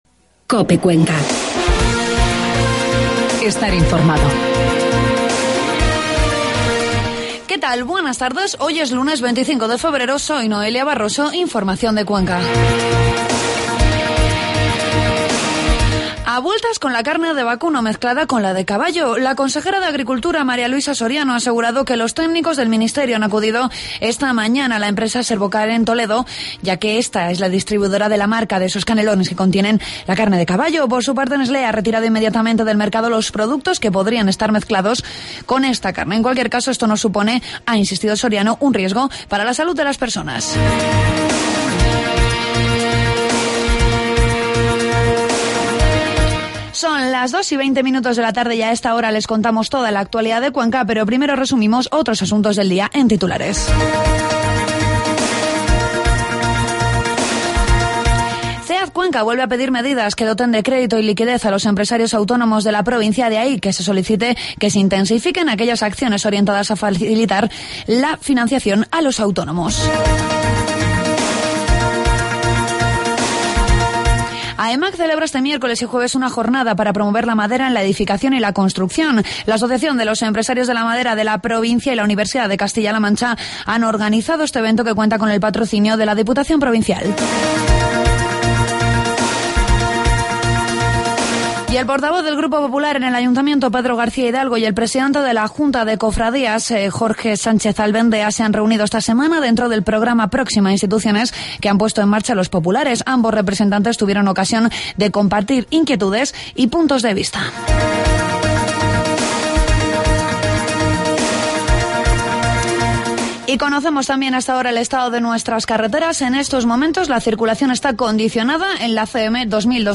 Toda la información de la provincia de Cuenca en los informativos de mediodía de COPE